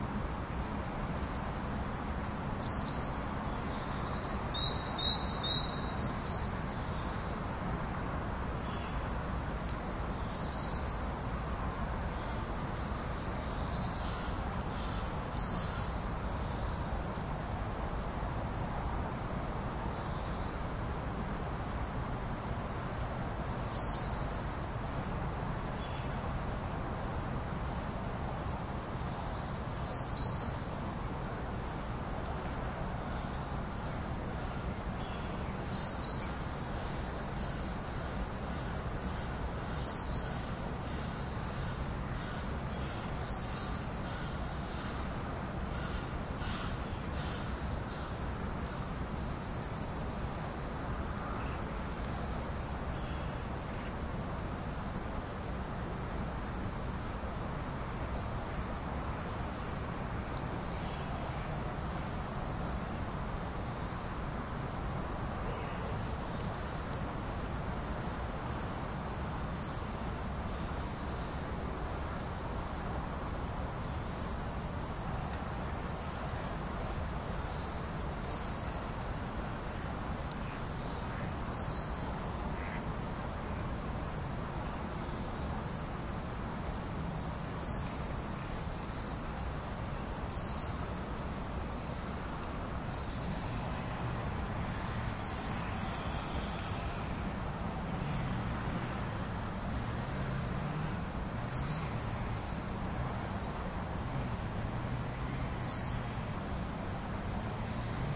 描述：3/3 奶牛，鞭子鸟和笑翠鸟（以及其他）的野外记录。小牛正在从母亲身上消瘦，所以有很多遥远和近乎呻吟。 这张录音中有一些错误的脚步声。 在带有逼真PZM麦克风的Zoom H4n上录制。 Adobe Audition中的降噪功能
Tag: 鸣叫 动物的声音 景观 放牧 农业 养殖 牧场 农业 畜牧业 土地 乡村 澳大利亚 热带 国家 农村 一般的噪声 农田